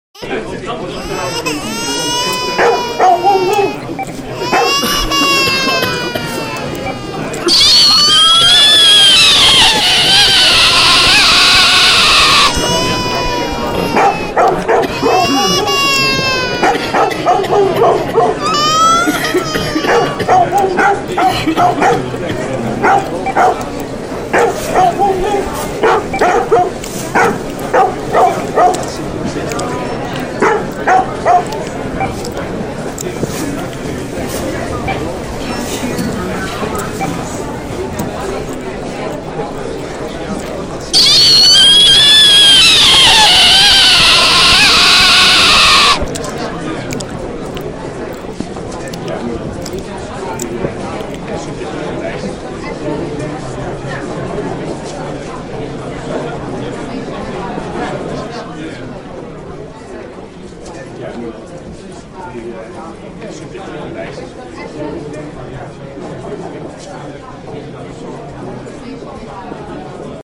I used an app called Mix Pad to cut and mix several sounds and noises together to create an overstimulating soundscape. This would be used to show neutotypical people what a shopping experience might be like to someone who is neurodivergent. I included noises like coughing, beeps, crying children, dogs barking, crunching and general chatter.
Overwhelming supermarket soundscape